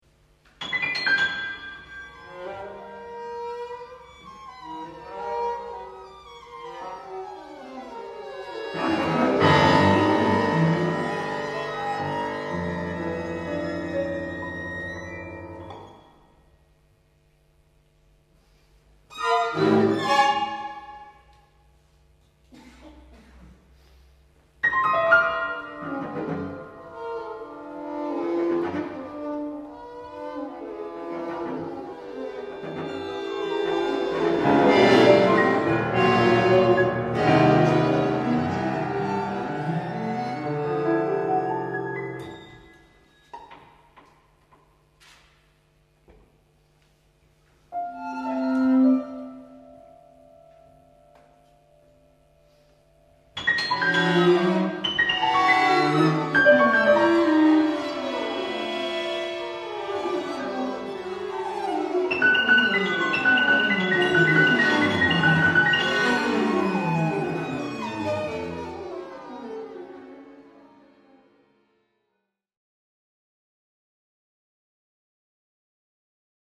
Live recording
piano, accordion, string quartet duration: 5′